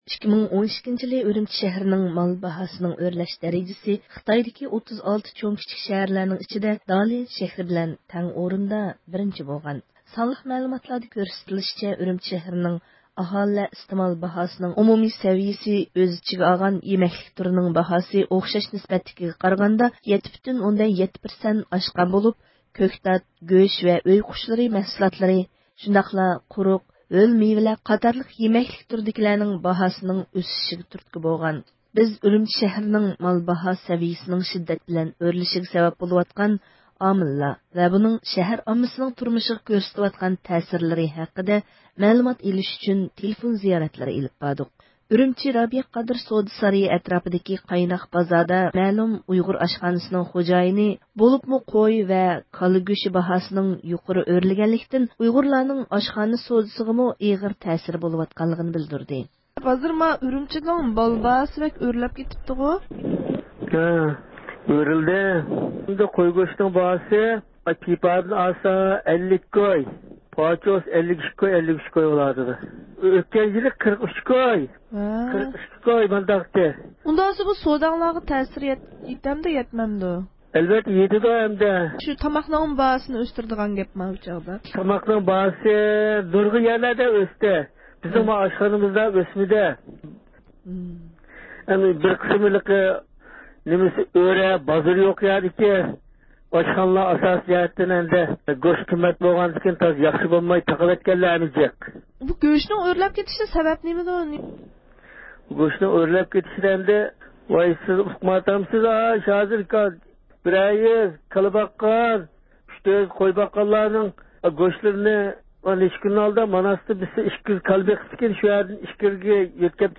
بىز ئۈرۈمچى شەھىرىنىڭ مال باھا سەۋىيىسىنىڭ شىددەت بىلەن ئۆرلىشىگە سەۋەب بولۇۋاتقان ئامىللار ۋە بۇنىڭ شەھەر ئاممىسىنىڭ تۇرمۇشىغا كۆرسىتىۋاتقان تەسىرلىرى ھەققىدە مەلۇمات ئېلىش ئۈچۈن تېلېفون زىيارەتلىرى ئېلىپ باردۇق، ئۈرۈمچى رابىيە قادىر سودا سارىيى ئەتراپىدىكى قايناق بازاردىكى مەلۇم ئۇيغۇر ئاشخانىسىنىڭ خوجايىنى، بولۇپمۇ قوي ۋە كالا گۆشى باھاسى يۇقىرى ئۆرلىگەنلىكتىن ئۇيغۇرلارنىڭ ئاشخانا سودىسىغىمۇ ئېغىر تەسىرى بولغانلىقىنى بىلدۈردى.